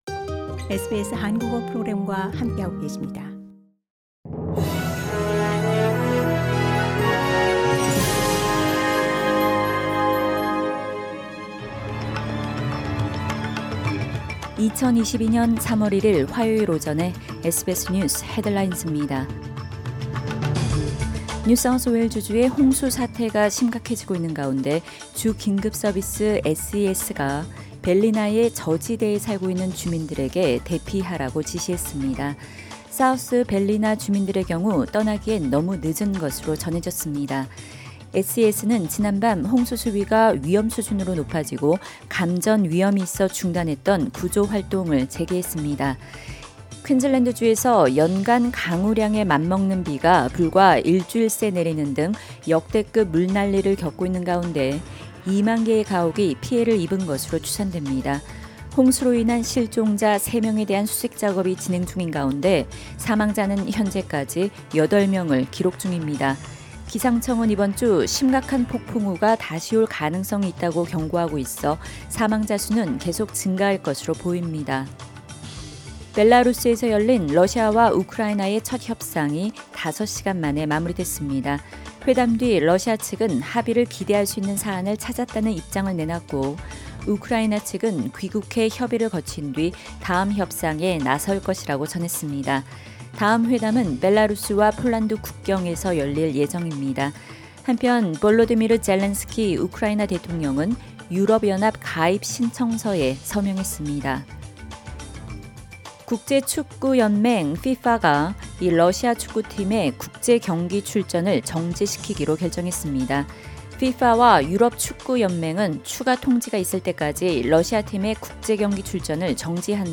SBS News Headlines…2022년 3월 1일 화요일 오전 뉴스
2022년 3월 1일 화요일 오전 SBS 뉴스 헤드라인즈입니다.